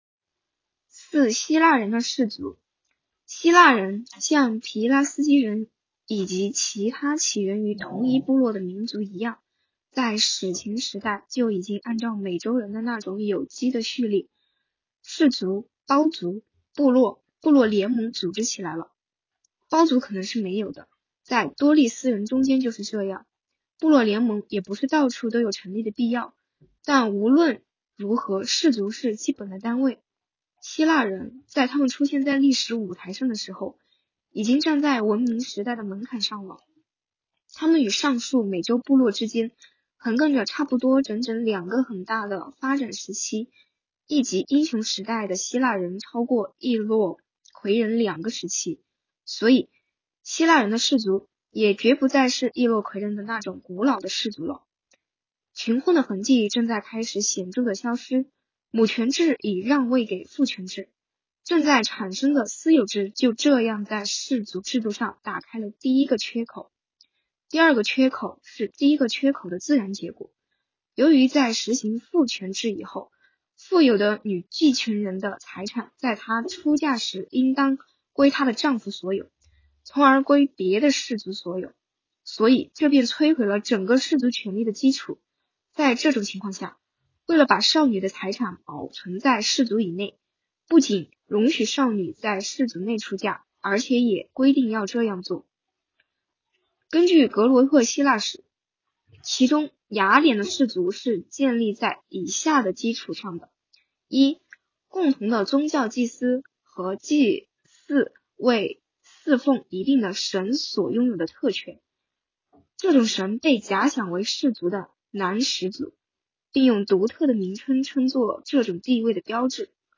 “读经典、悟原理”——2025年西华大学马克思主义经典著作研读会接力诵读（03期）